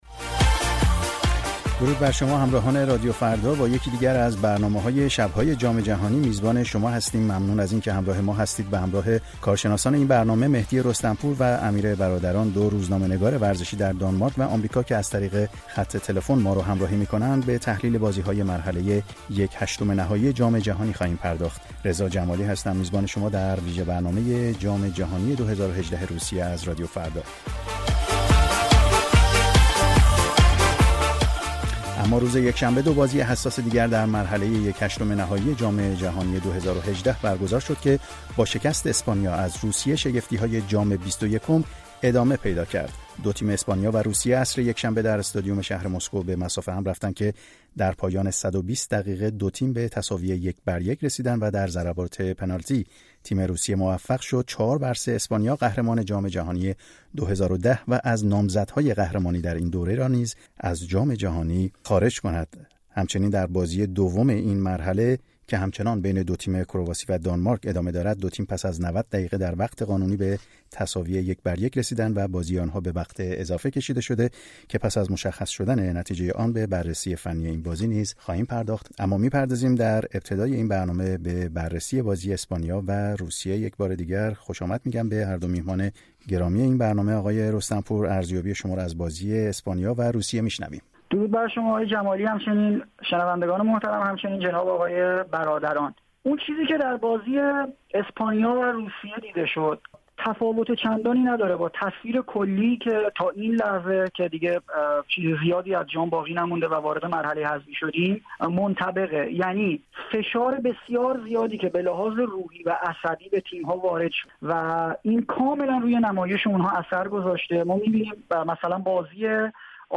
میزگرد بررسی بازی‌های جام جهانی فوتبال در روسیه ۲۰۱۸